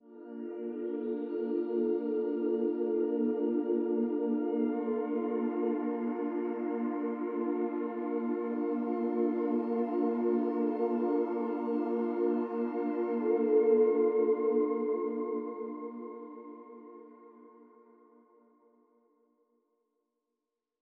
crystals_and_voices.wav